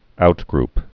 (outgrp)